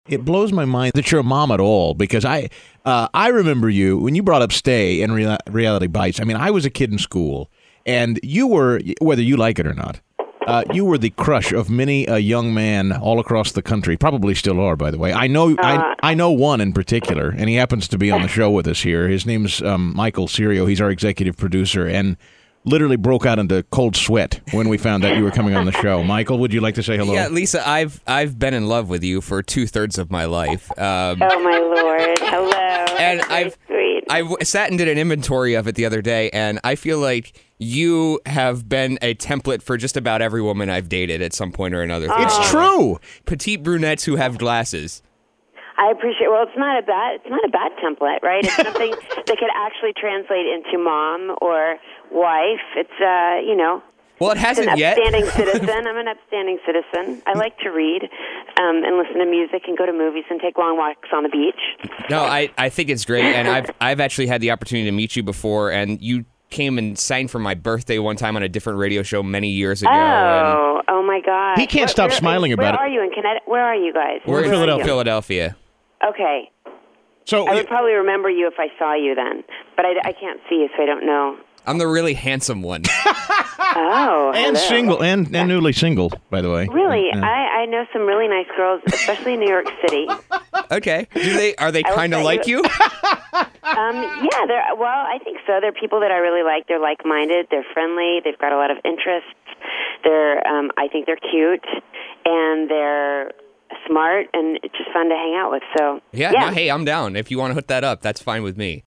Lisa Loeb On Talk Radio 1210 WPHT